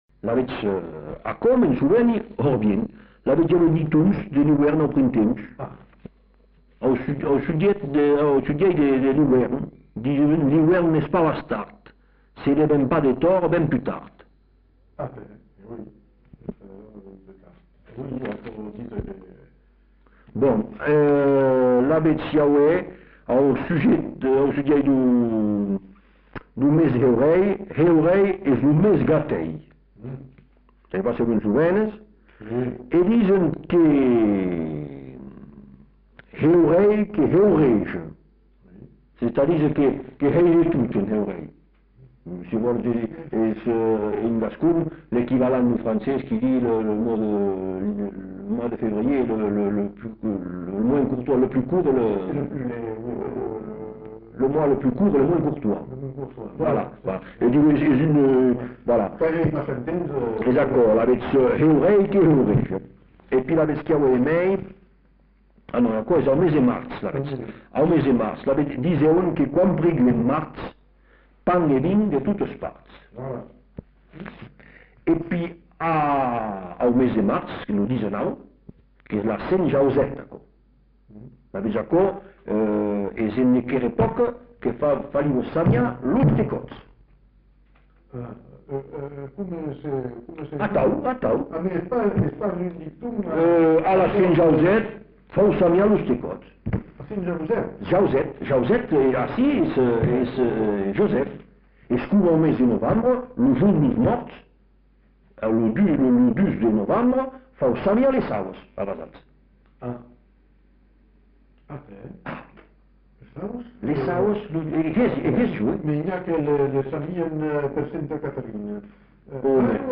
Lieu : Bazas
Genre : témoignage thématique/forme brève
Effectif : 1
Type de voix : voix d'homme
Production du son : récité
Classification : proverbe-dicton